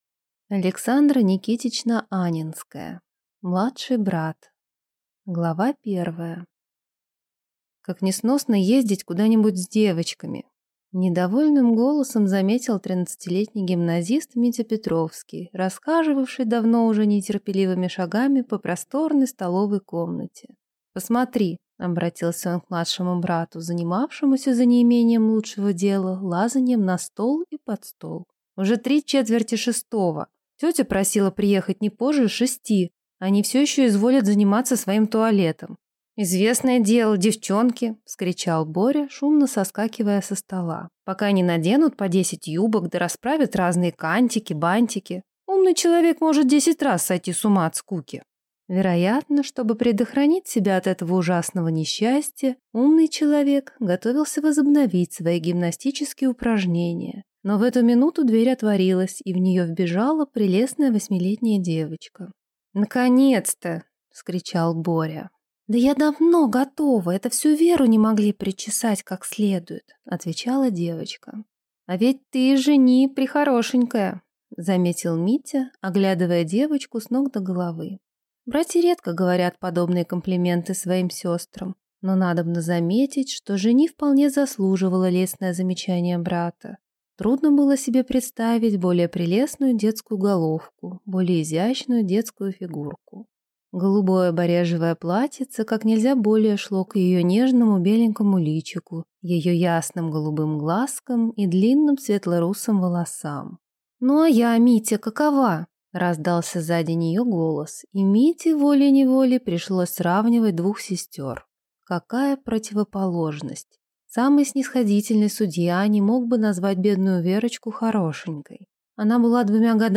Аудиокнига Младший брат | Библиотека аудиокниг